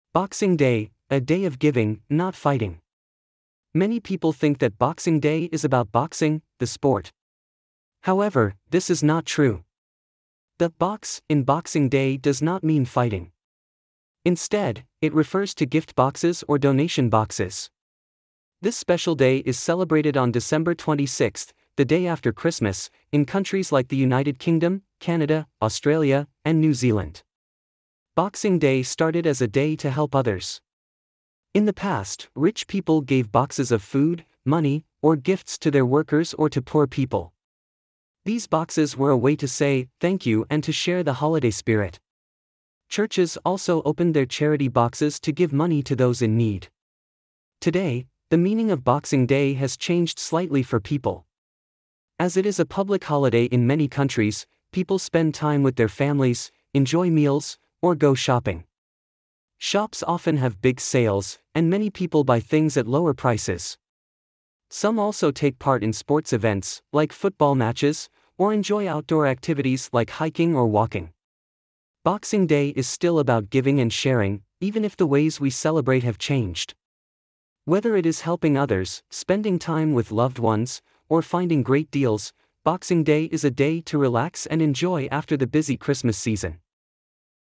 朗讀